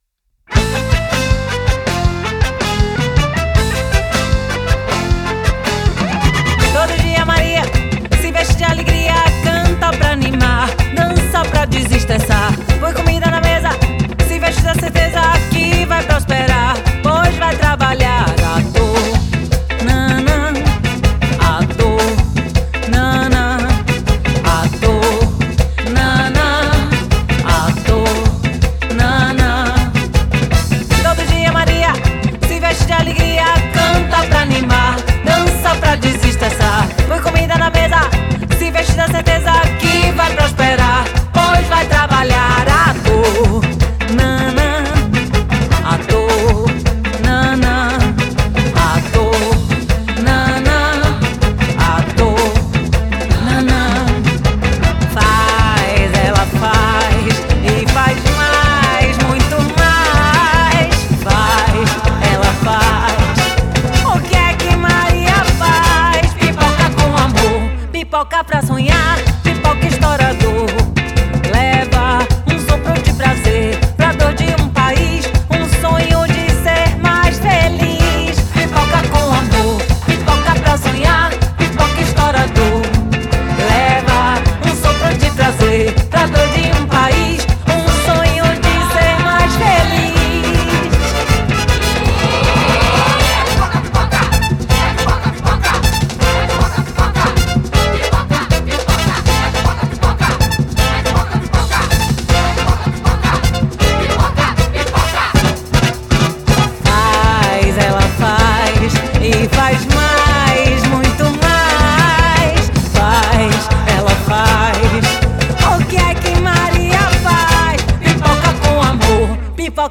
супружеский и творческий дуэт из Бразилии